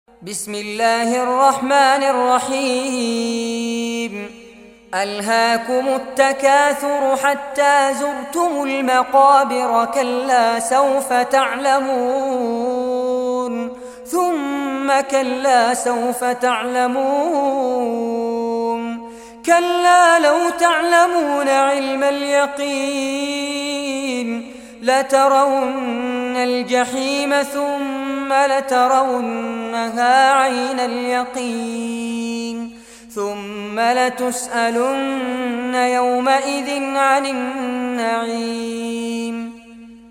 Surah Takasur Recitation by Fares Abbad
Surah Takasur, listen or play online mp3 tilawat / recitation in Arabic in the beautiful voice of Sheikh Fares Abbad.